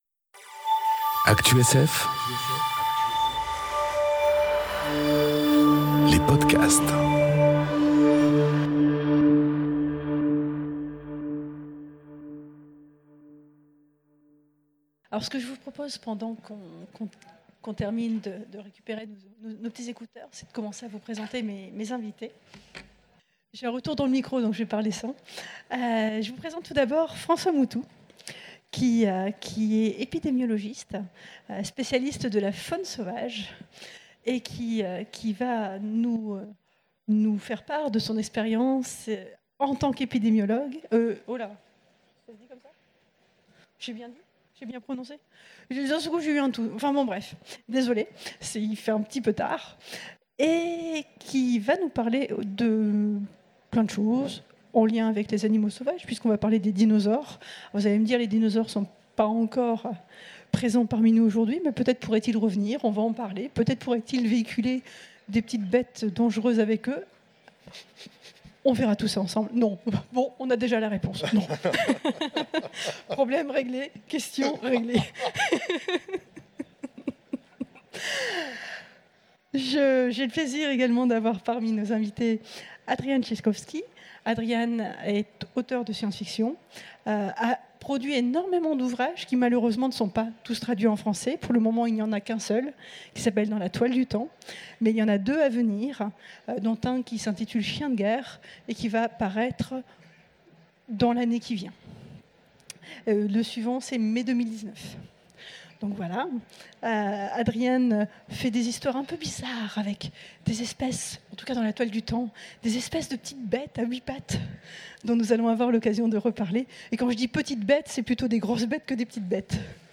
Conférence Jurassic Park ou le remède à la Grande Exinction enregistrée aux Utopiales 2018